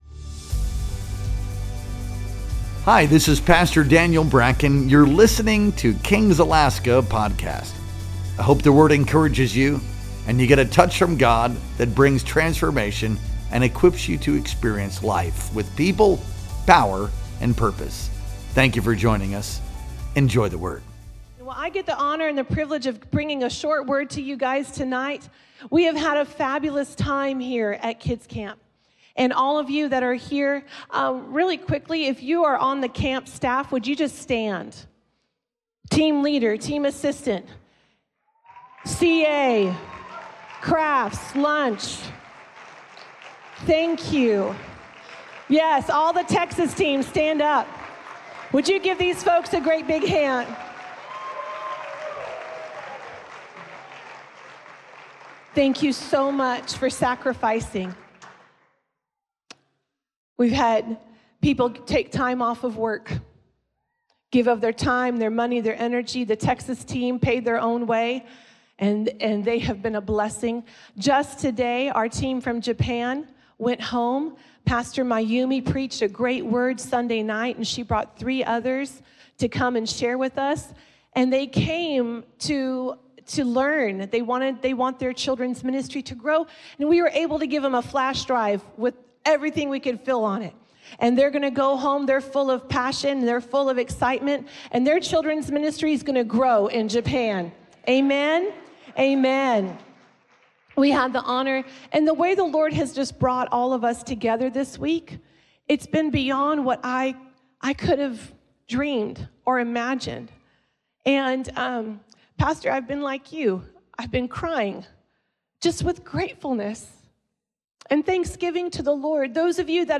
Our Wednesday Night Worship Experience streamed live on June 25th, 2025.